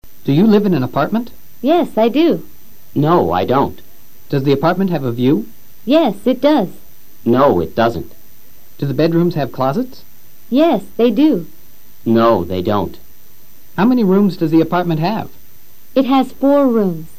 Escucha al profesor leyendo preguntas en tiempo PRESENTE SIMPLE.